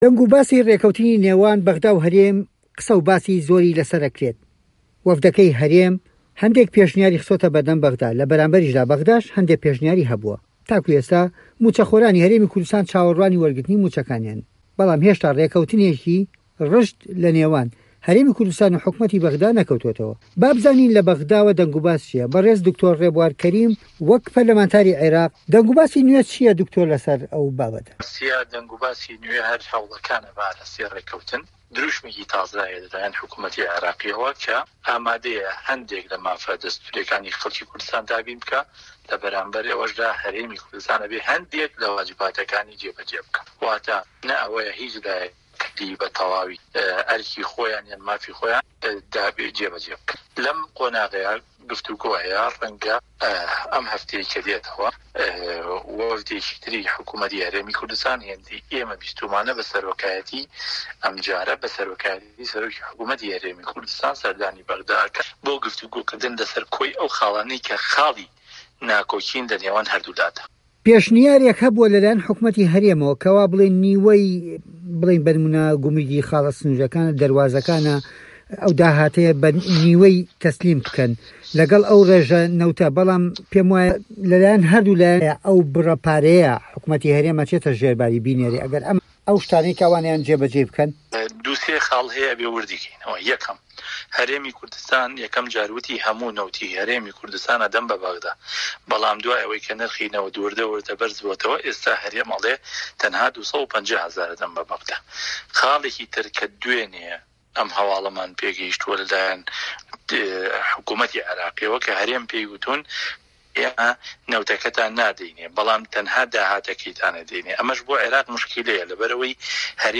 وتووێژ لەگەڵ دکتۆر ڕێبوار